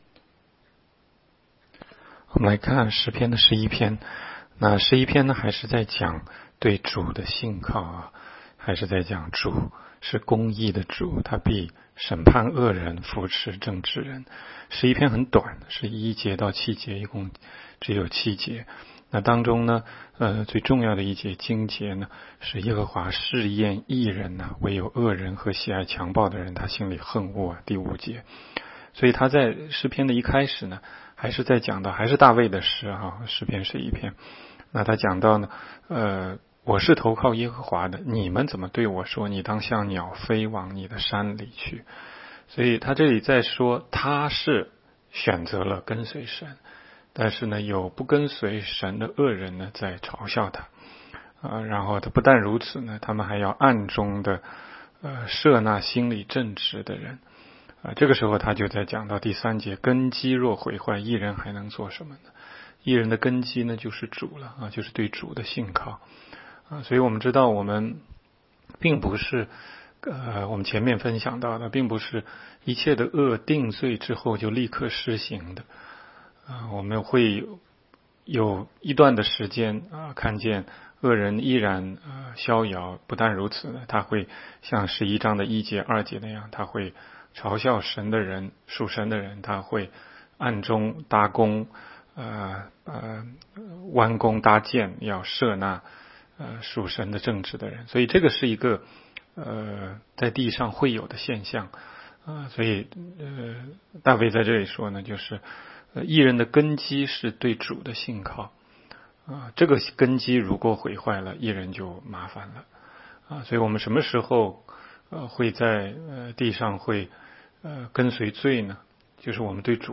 16街讲道录音 - 每日读经-《诗篇》11章